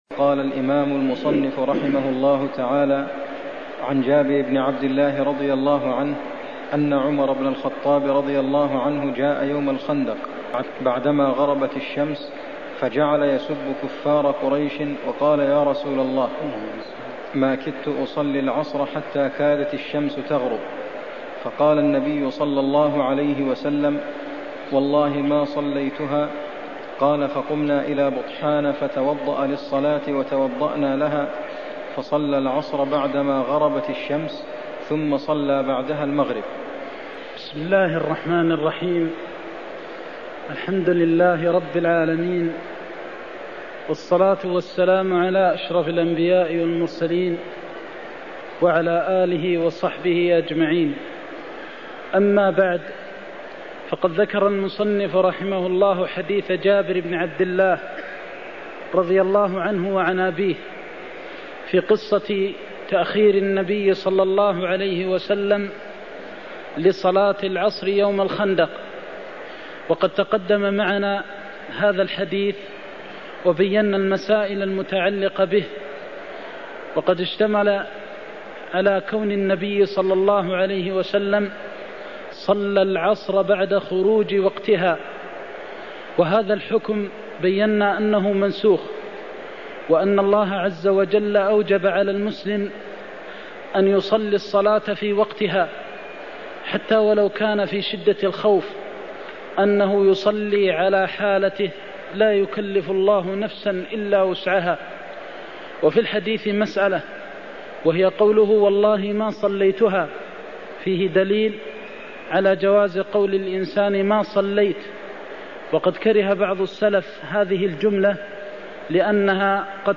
المكان: المسجد النبوي الشيخ: فضيلة الشيخ د. محمد بن محمد المختار فضيلة الشيخ د. محمد بن محمد المختار تأخير النبي لصلاة العصر إلى غروب الشمس يوم الخندق (53)